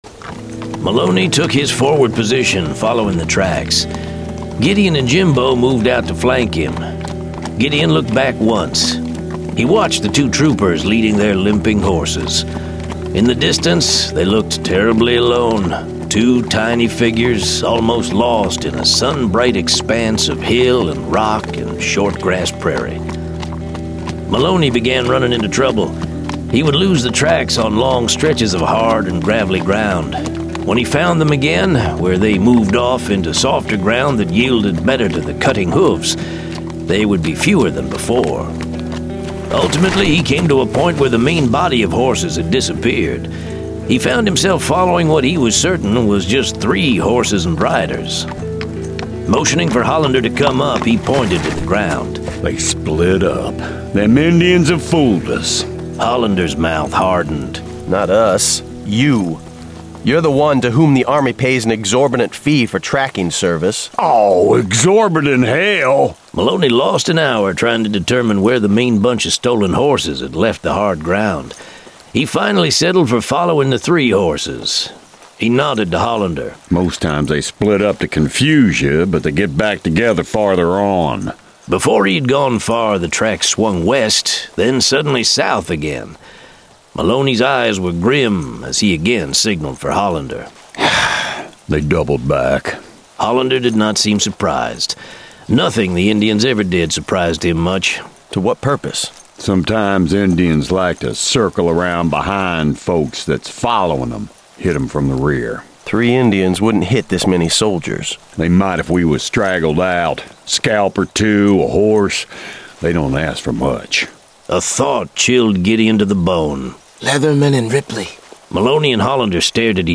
The Wolf and the Buffalo 1 of 2 [Dramatized Adaptation]